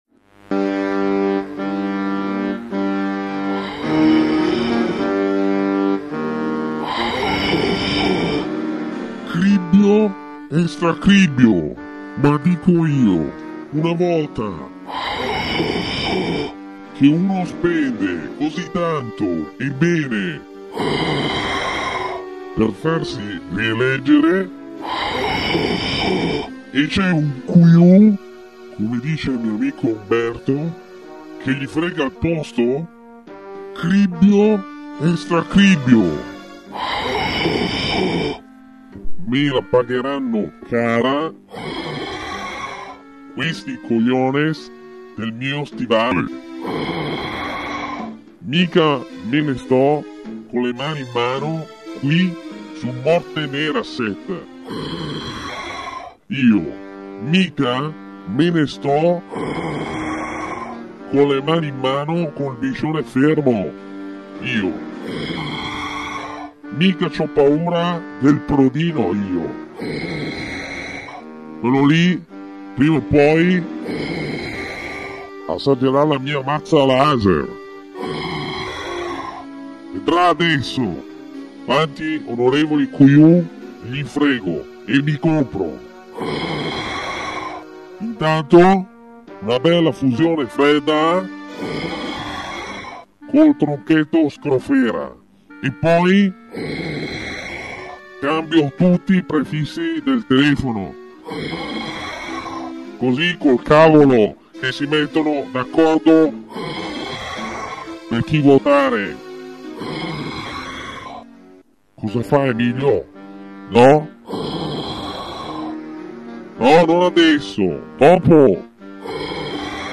Piccola produzione fatta in casa.